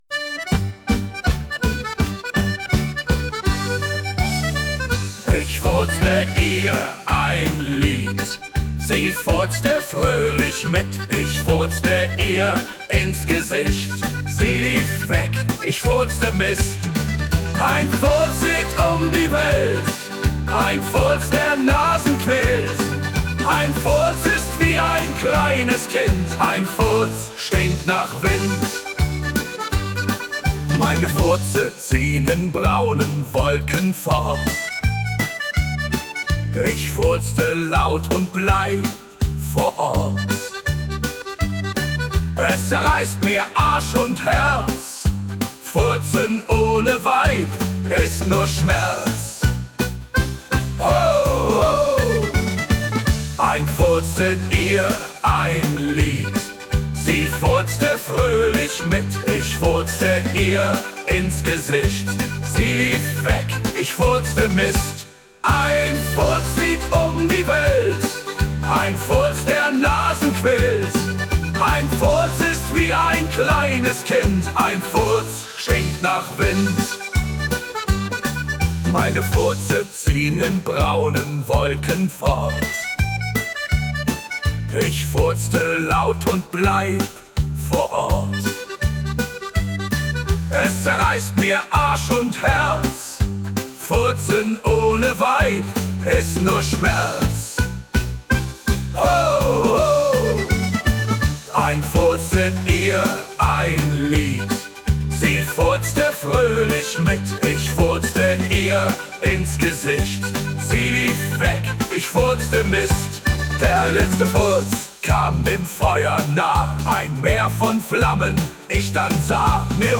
Januar 2025 um 07:35 49 Als das Lied vorüber war sang er laut sein eigenes neues lied /uploads/db5979/original/2X/1/195b1d711a34d0a9b7e6fd7318f793a4a36abd5f.mp3 Musik ist KI generiert! 1 „Gefällt mir“